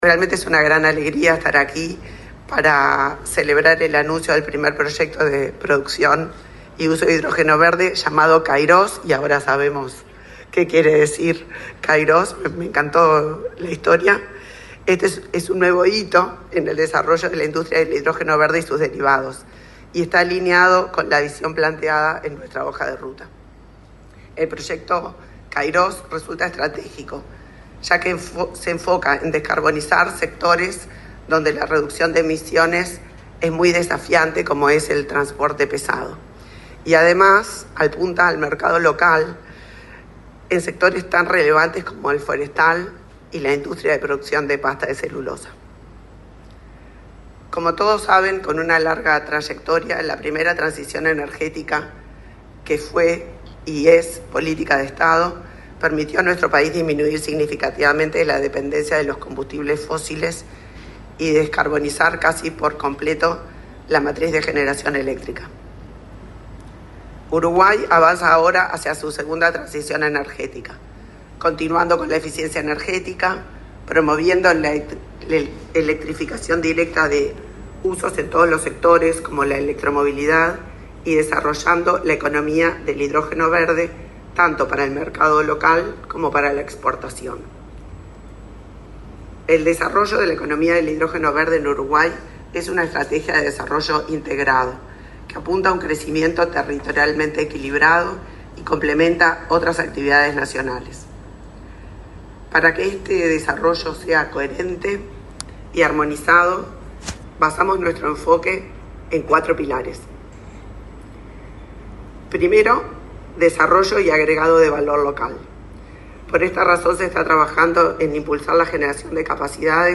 Palabras de la ministra de Industria, Elisa Facio
Este miércoles 30 en el Laboratorio Tecnológico del Uruguay, la ministra de Industria, Elisa Facio, participó en el acto de presentación de la obra de